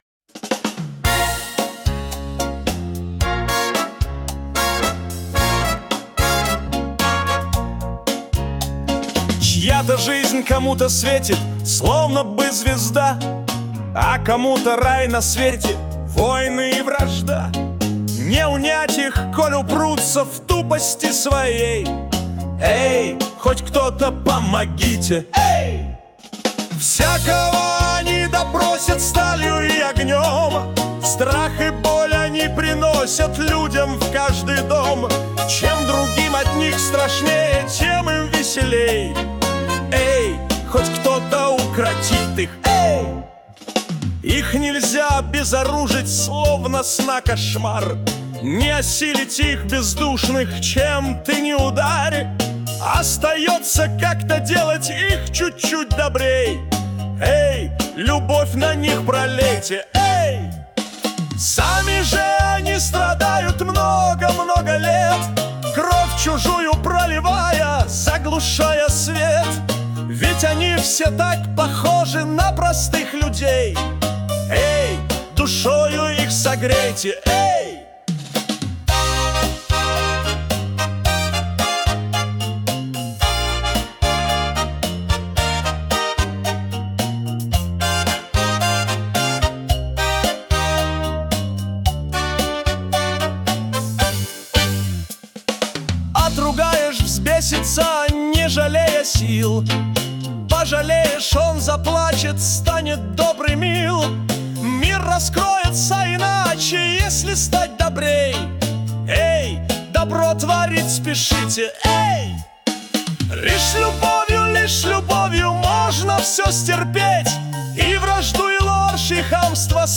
кавер-версия
Для Медитаций